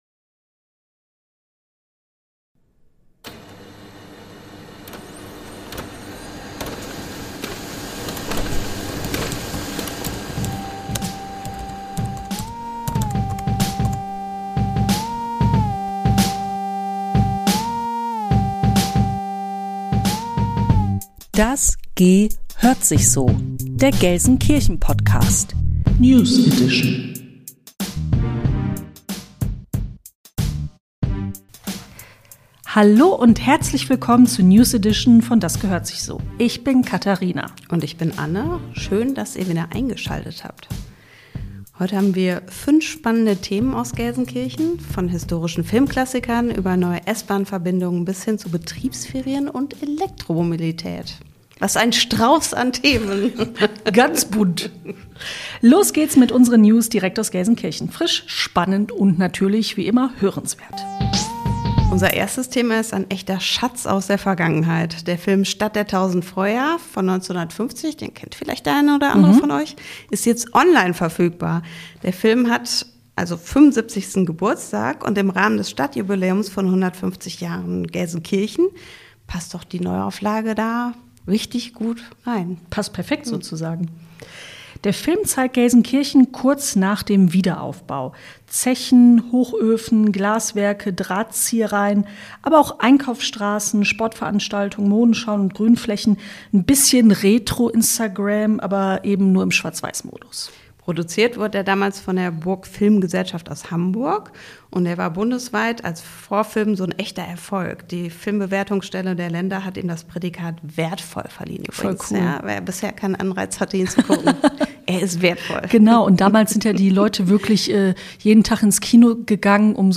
Nachrichten aus Deiner Stadt - direkt ins Ohr